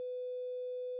5. Oberwelle 500Hz
APRecht500Hz.au